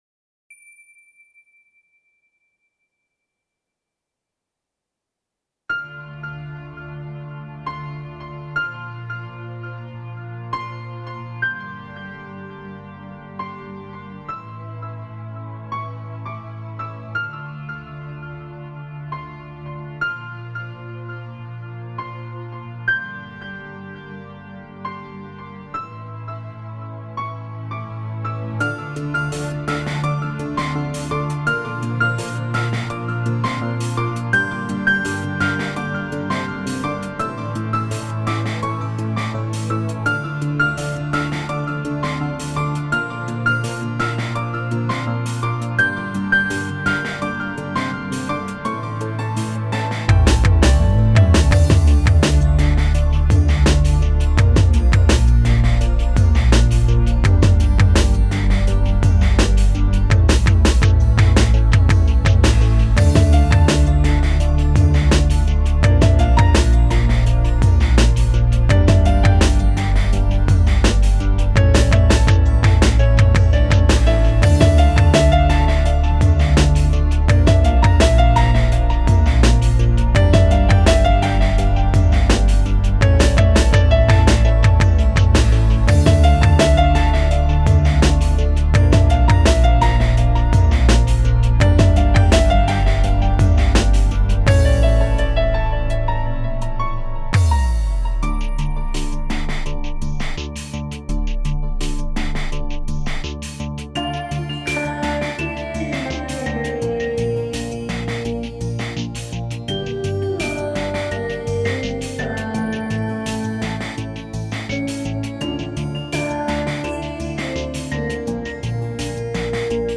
грустная! слышен дождик. прикольно!